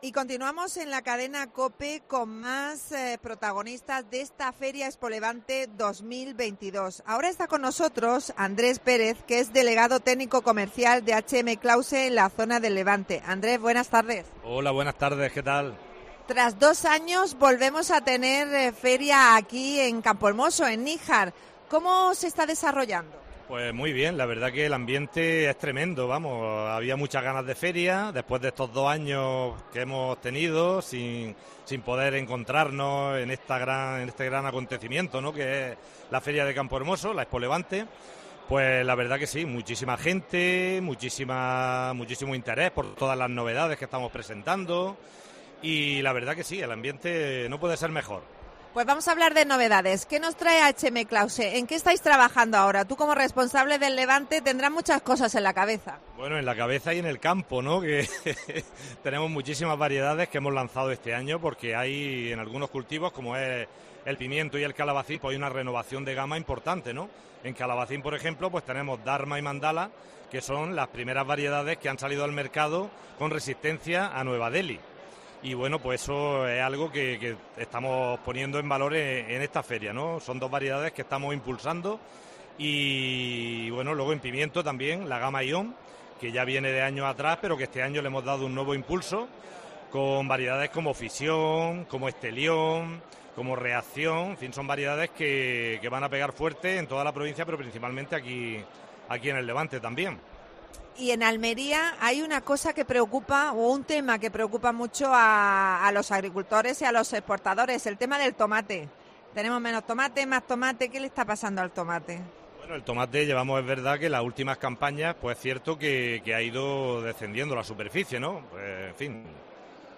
AUDIO: Especial ExpoLevante. Entrevista